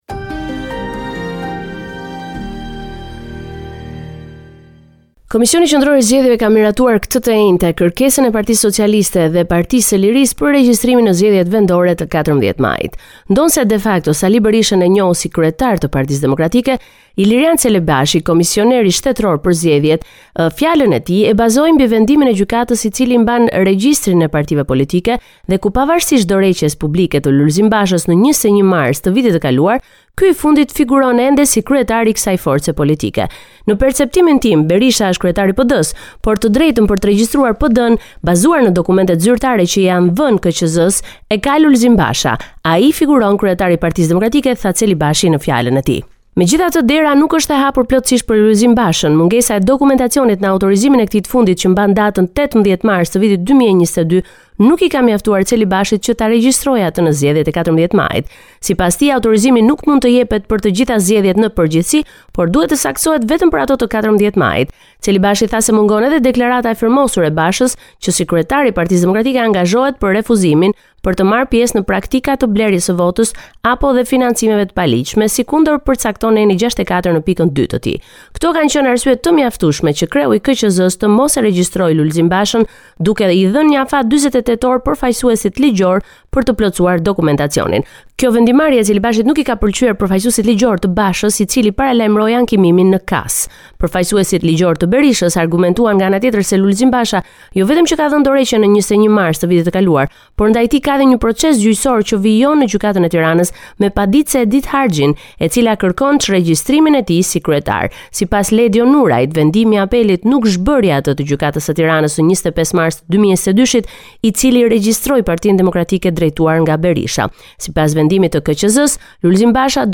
Raporti me të rejat më të fundit nga Shqipëria.